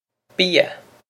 bia bee-ah
This is an approximate phonetic pronunciation of the phrase.